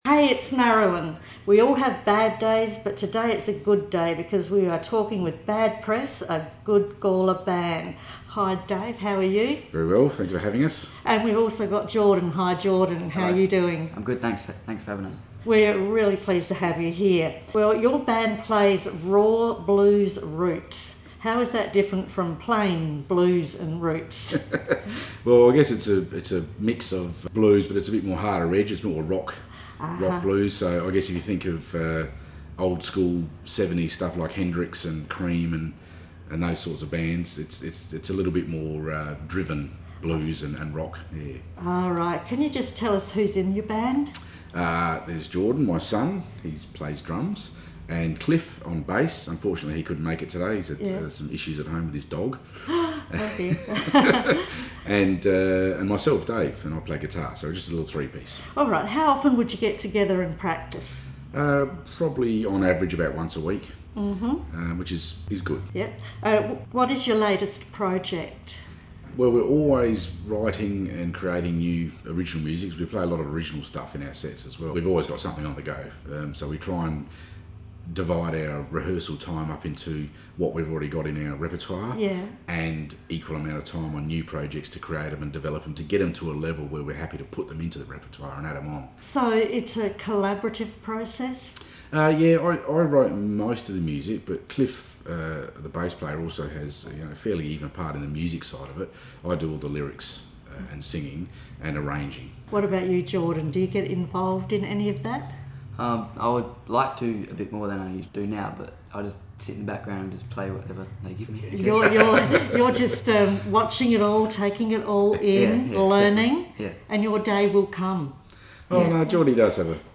Community Interviews